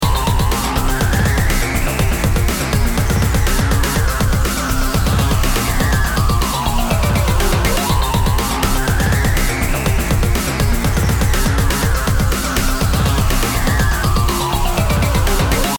528hz BPM120-129 Game Instrument Soundtrack インストルメント
BPM 122